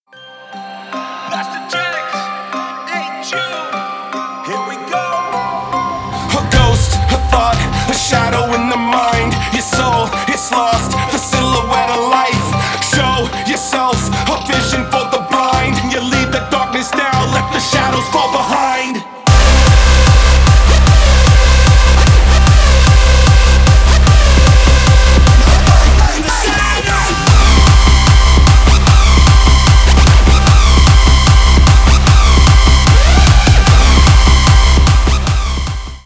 Рэп и Хип Хоп # Танцевальные
клубные # громкие